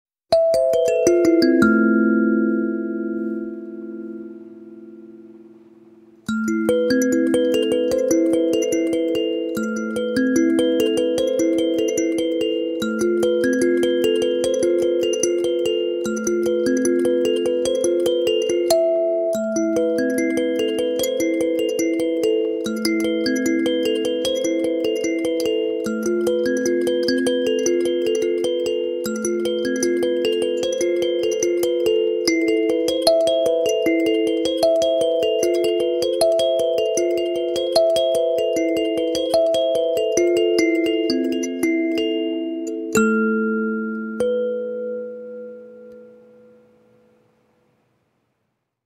Kalimba B9
The B9, as we call it, is a small, handy kalimba with a solid resonance corpus of American cherrywood.
The vibrations of the nine tines can be easily felt in the hands.
A-Minor Pentatonic
(A4, C5, C4, A4, A3, F4, E4, E5, H/B4)
hokema-kalimba-b9-a-moll.mp3